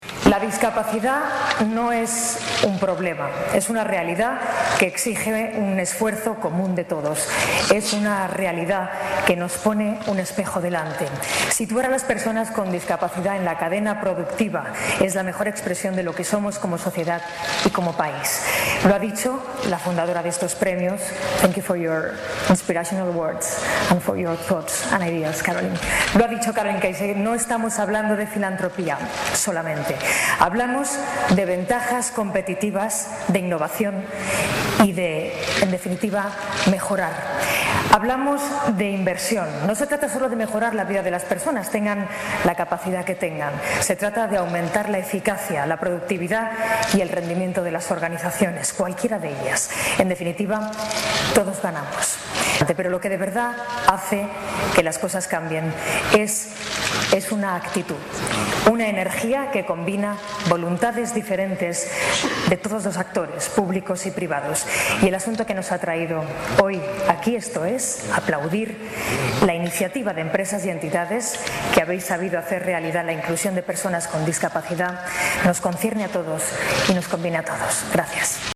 En definitiva, aun poniendo en valor que las políticas por la integración constituyen “la mejor expresión de lo que somos como sociedad y como país”, quiso poner el énfasis -ante un auditorio repleto de empresarios y emprendedores- en la rentabilidad, también económica, de estas medidas, oficiales y privadas.